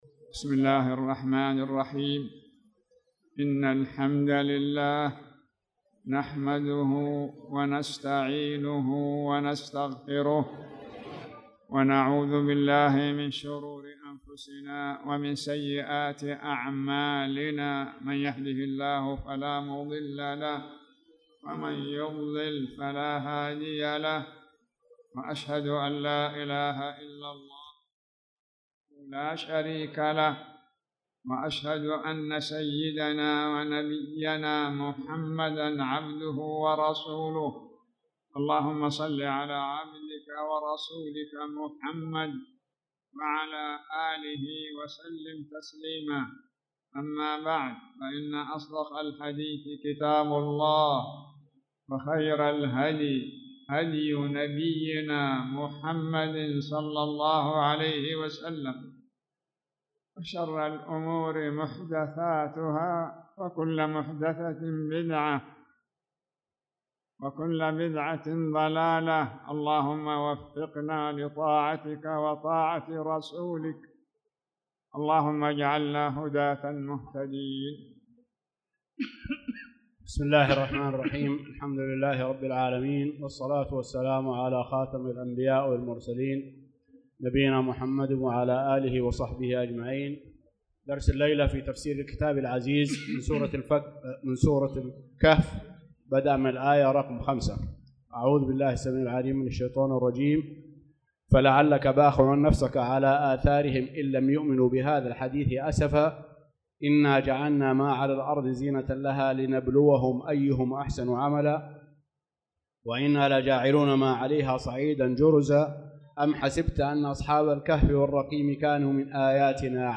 تاريخ النشر ١٥ شوال ١٤٣٧ هـ المكان: المسجد الحرام الشيخ